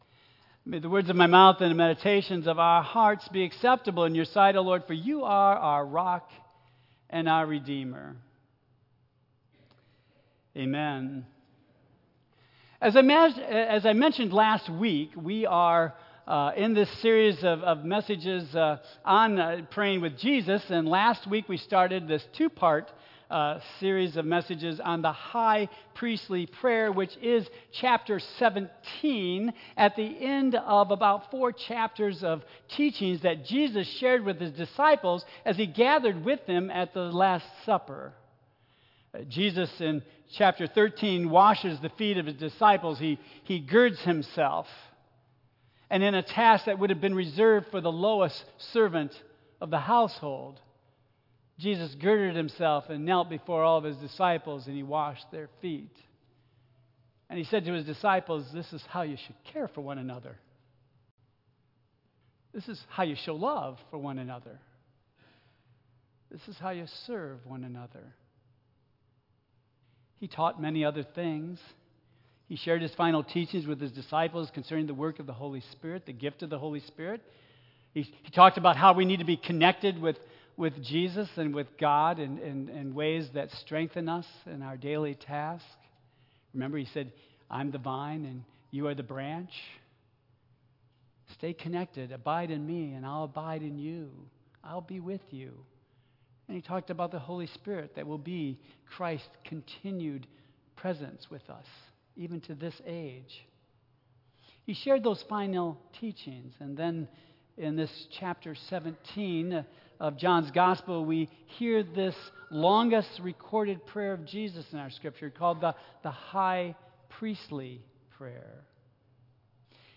Tagged with Michigan , Sermon , Waterford Central United Methodist Church , Worship Audio (MP3) 9 MB Previous The High Priestly Prayer (Part 1) Next Easter Choral Cantata